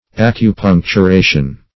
Acupuncturation \Ac`u*punc`tu*ra"tion\, n.
acupuncturation.mp3